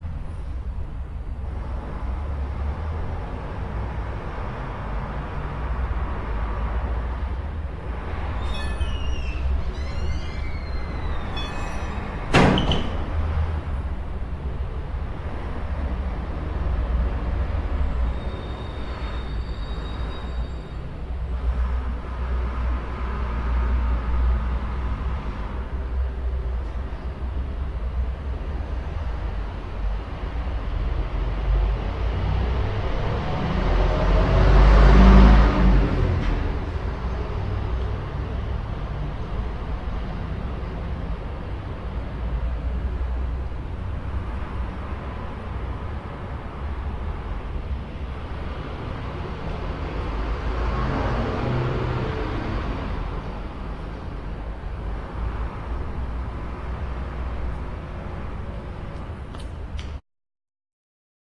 street6.rm